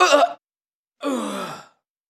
dead.wav